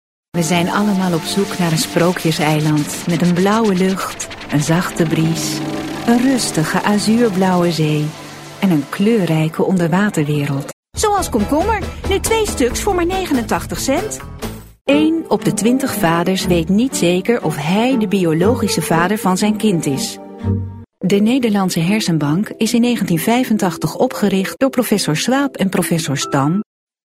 stem acteren